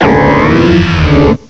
cry_not_malamar.aif